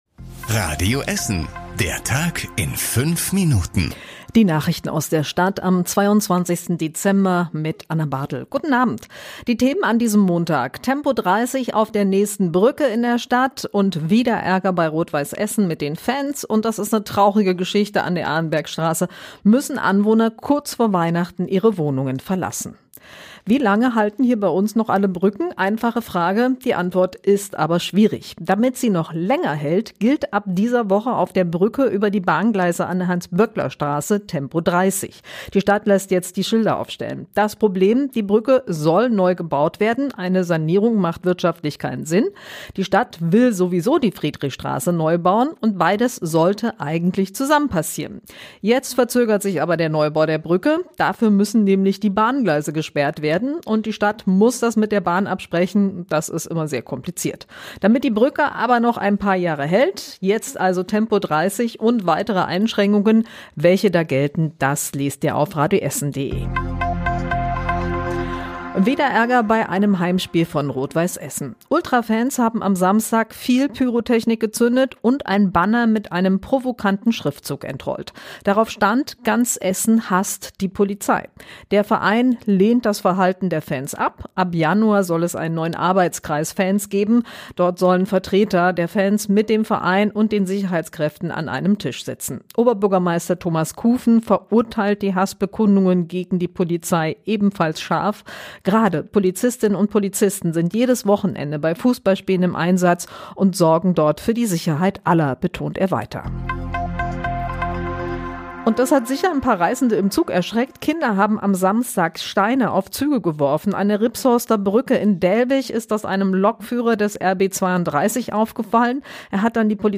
Die wichtigsten Nachrichten des Tages in der Zusammenfassung
Nachrichten